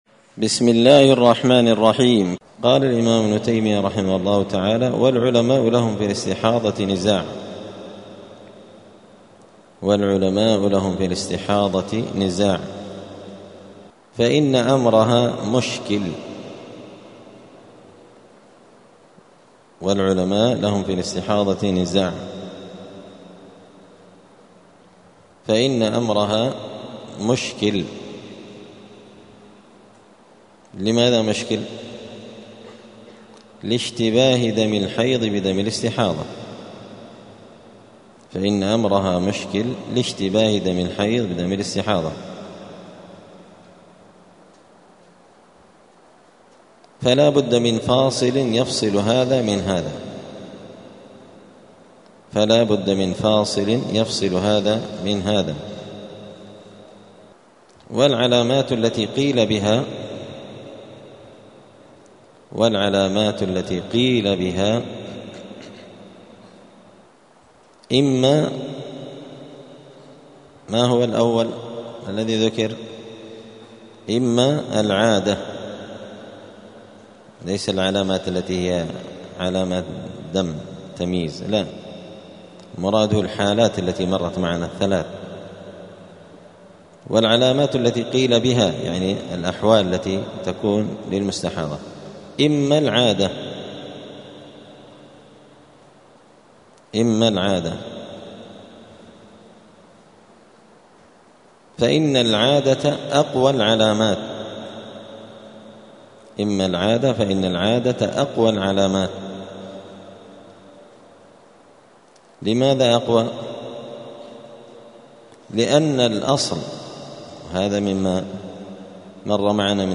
دار الحديث السلفية بمسجد الفرقان قشن المهرة اليمن
*الدرس الثامن والتسعون [98] {باب الحيض أحوال الاستحاضة}*